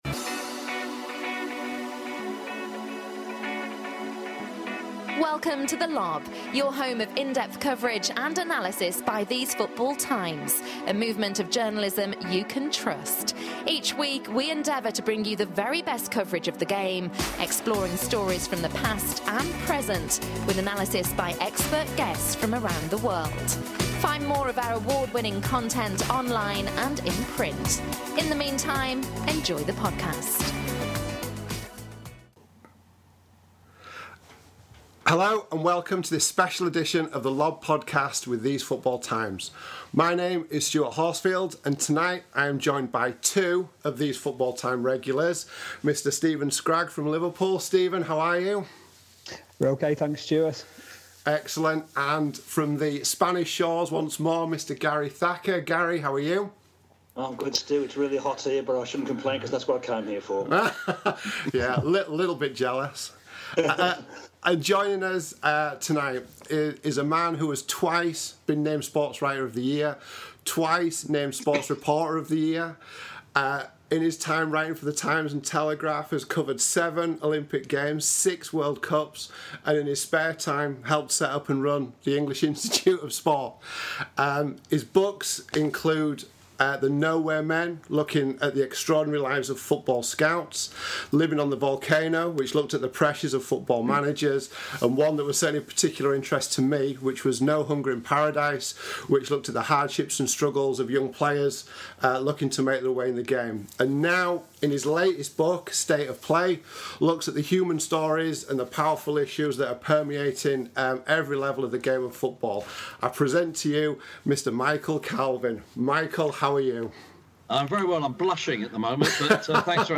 Award-winning author Michael Calvin joins the panel to discuss subjects from his outstanding new book State of Play, which explores football beneath its skin, from morality and mental health to its social impact and future.